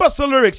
VOX SHORTS-1 0005.wav